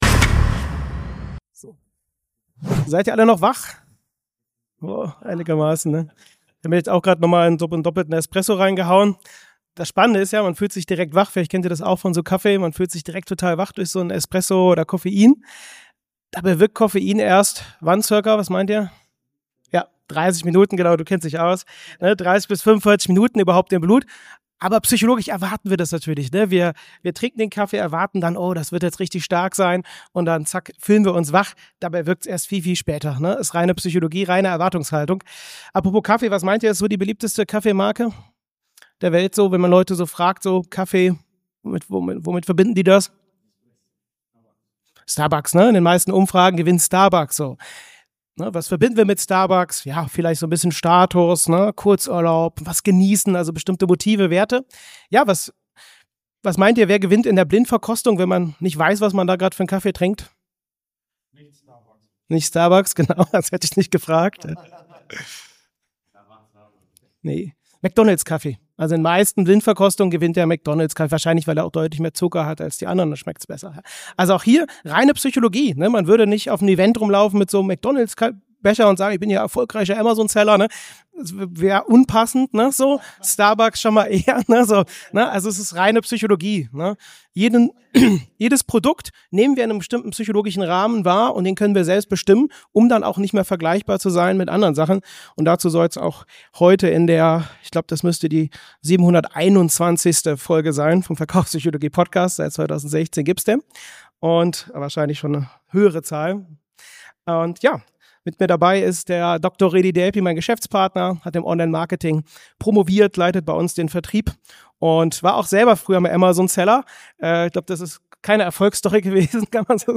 Diese Folge ist die Aufzeichnung vom Livetalk der AMZHackers Konferenz in Berlin.